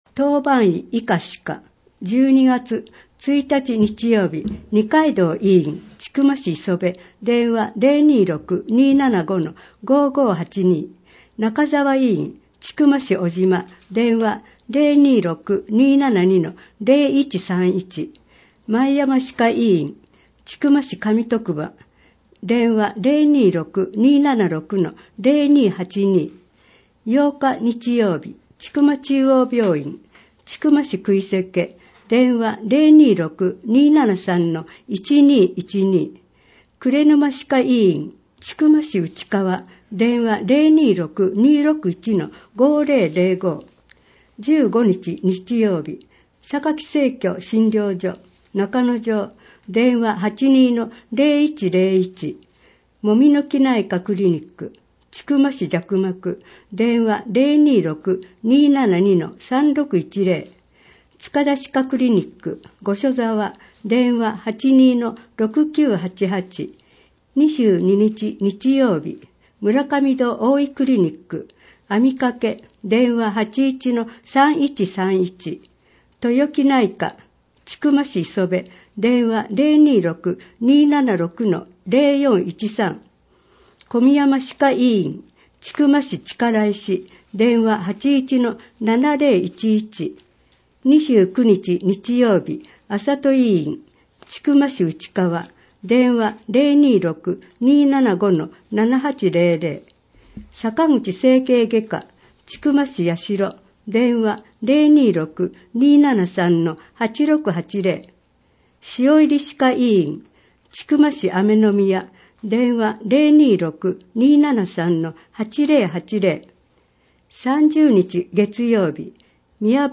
また、音訳ボランティアサークルおとわの会のみなさんによる広報の音訳版のダウンロードもご利用ください。